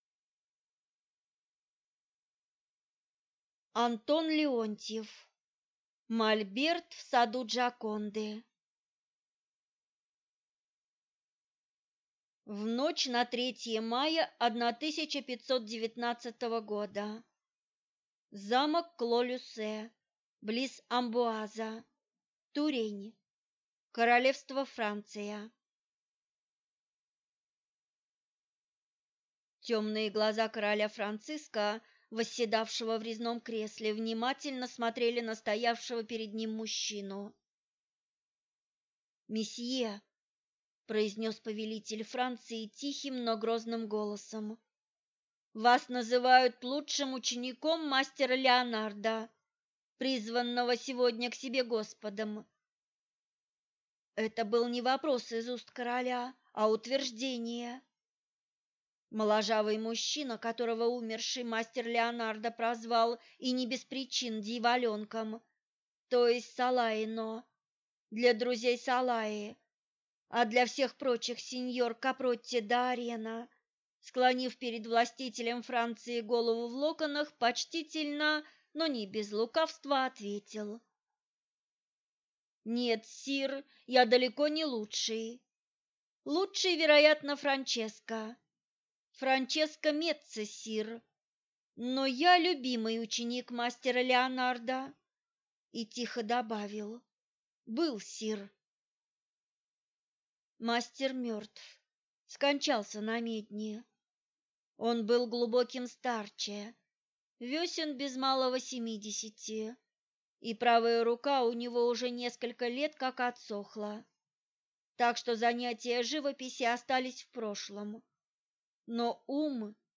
Аудиокнига Мольберт в саду Джоконды | Библиотека аудиокниг